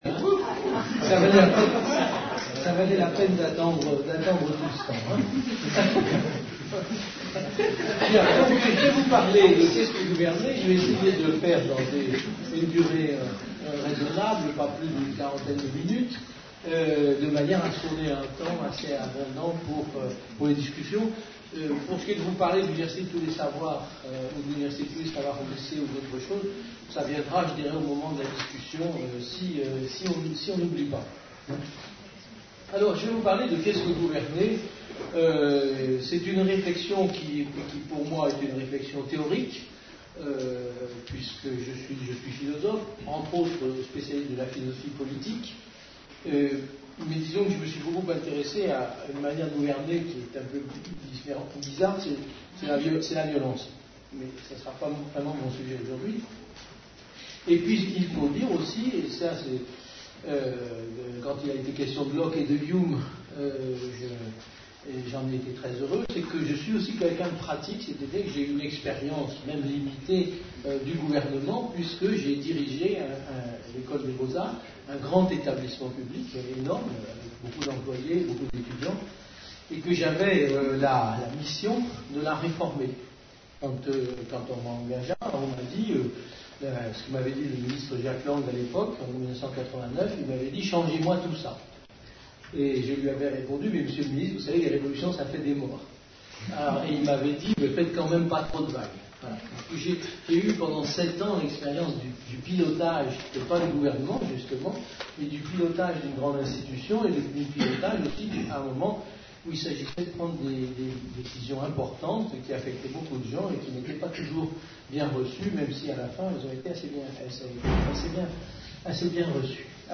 Un conférence de l'UTLS au lycée Lycée International de Strasbourg (67) Qu’est ce que gouverner ?Avec Yves Michaud (philosophe)